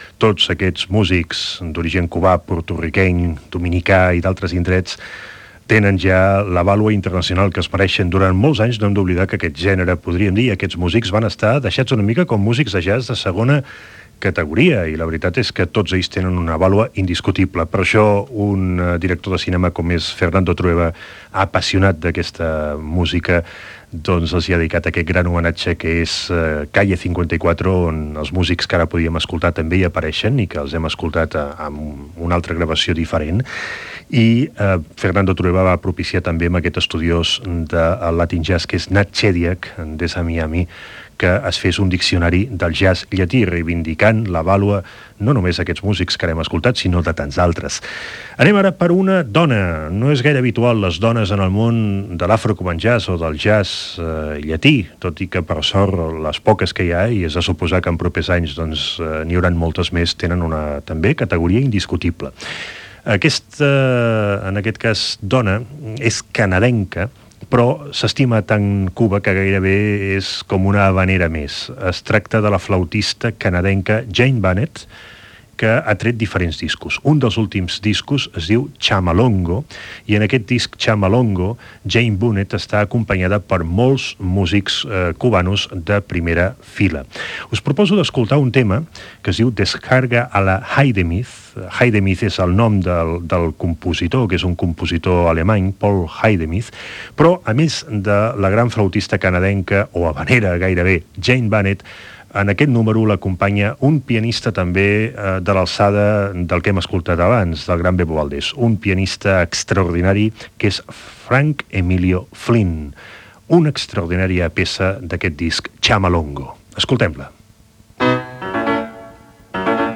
Musical
Versió musical de "L'altra ràdio" dedicada al jazz afrocubà emesa els mesos d'estiu, entre 1999 i 2005, amb 120 edicions.